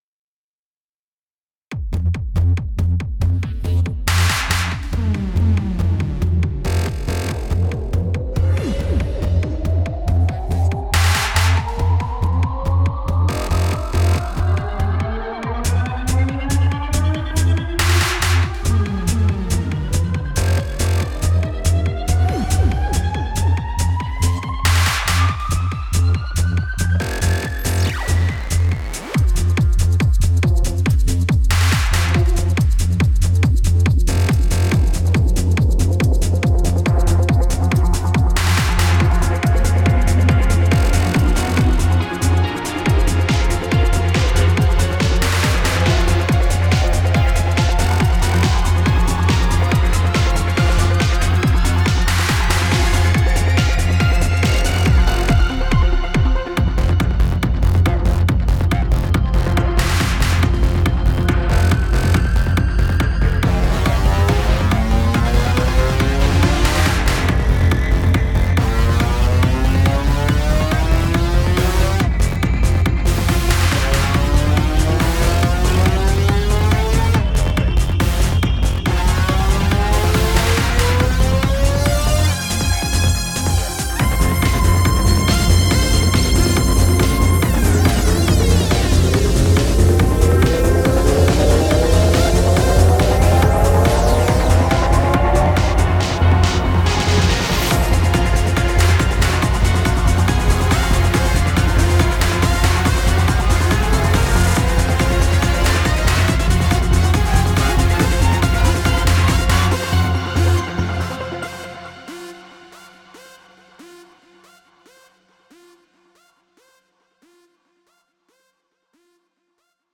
Hyperion is a premium collection of 100 cinematic EDM riser presets curated for Reveal Sound’s Spire.
From subtle, ethereal builds to monumental, universe-spanning crescendos, Hyperion delivers a spectrum of riser sounds designed to elevate trailers, cinematic soundscapes, and immersive atmospheres to cosmic heights.
• Cinematic-grade textures: from shimmering atmospheres to gravity-defying swells, each sound is designed for maximum impact.
• * The video and audio demos contain presets played from Hyperion sound bank, every single sound is created from scratch with Spire.
• * All sounds of video and audio demos are from Hyperion (except drums and additional arrangements).